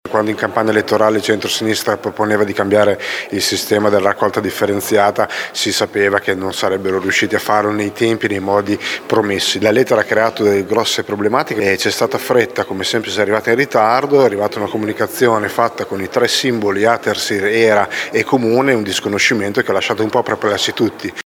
Piergiulio Giacobazzi, Forza Italia…